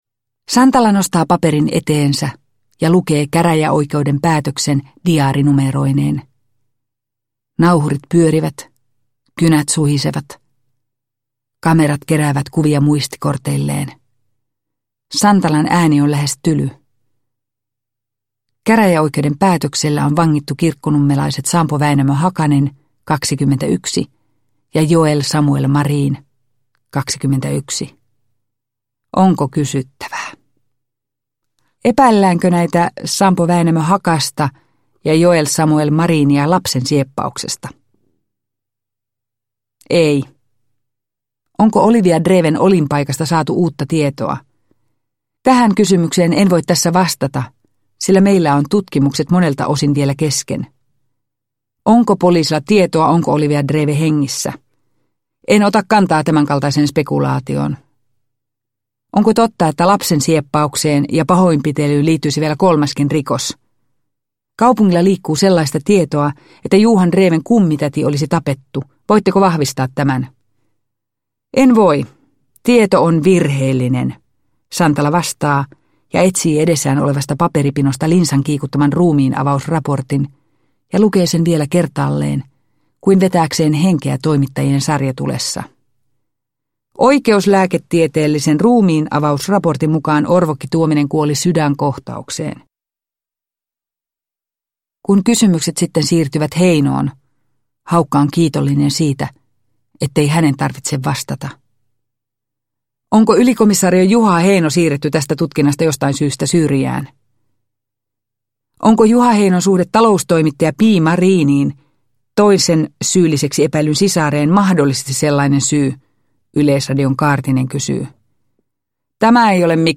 Uppläsare: Eppu Nuotio